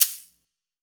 TC2 Perc7.wav